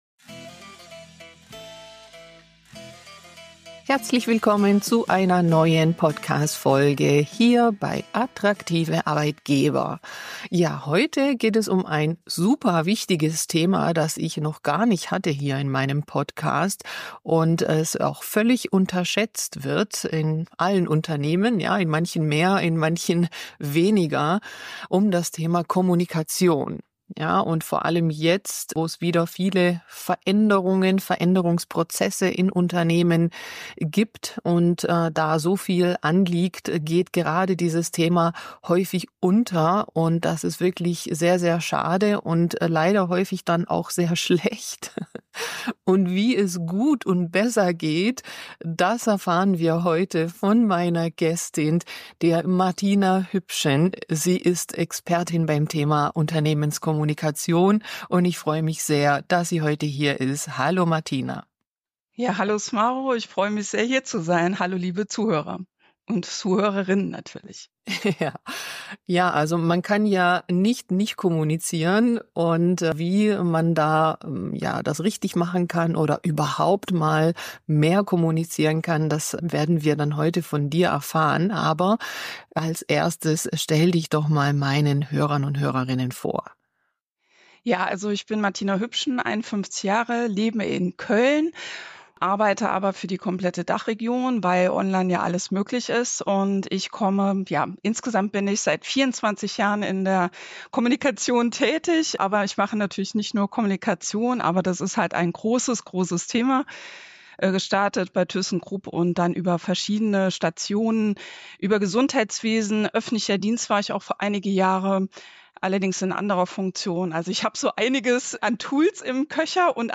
Kommunikation als Schlüssel für erfolgreiche Veränderungsprozesse - Interview